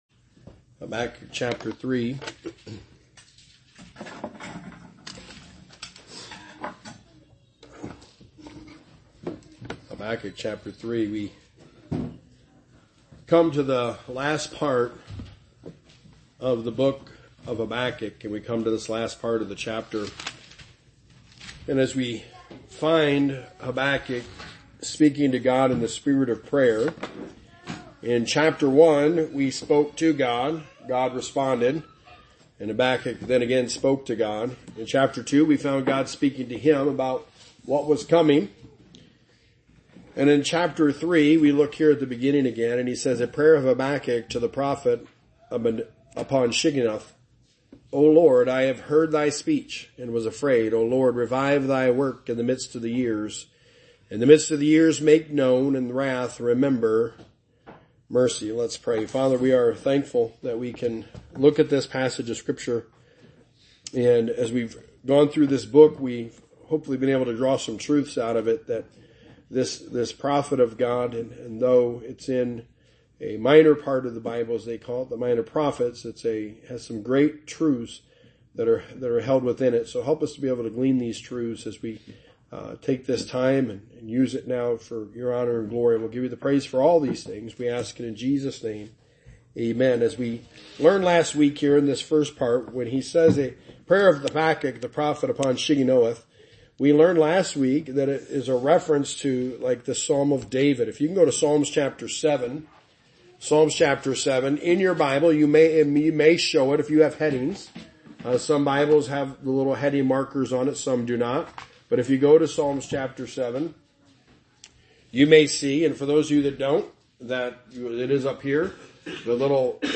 The Book of Habakkuk – Sermon #9
Habakkuk 1-3 Service Type: Sunday Morning The Book of Habakkuk